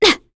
Selene-Vox_Attack1.wav